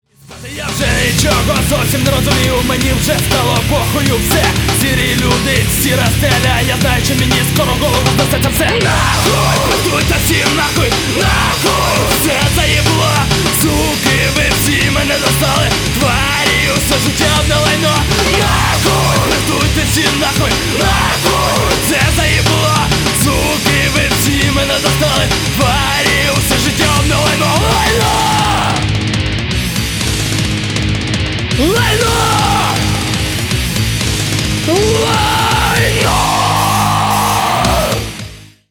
• Качество: 320, Stereo
мощные
hardcore
nu metal
злые
rap metal
агрессивные